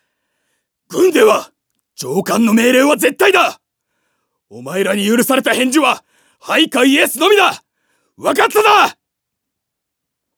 セリフ5